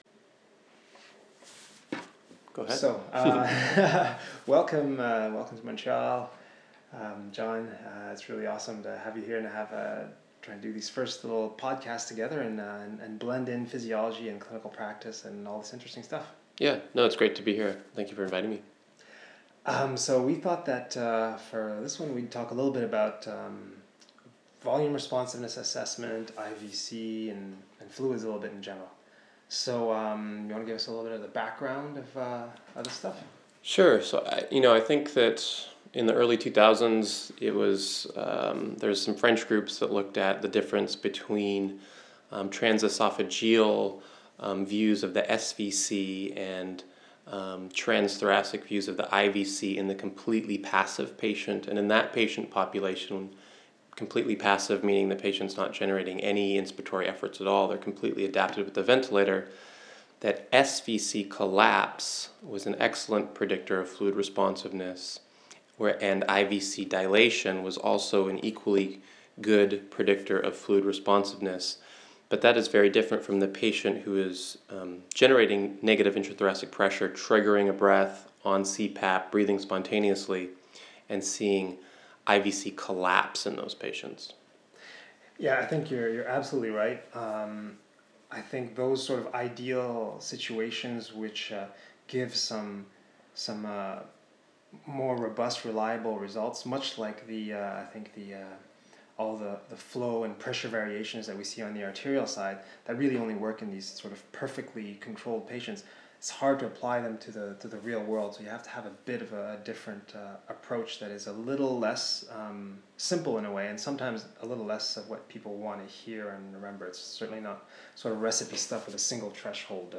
It was pretty much off the cuff, so do excuse our pauses and mostly my hmmms.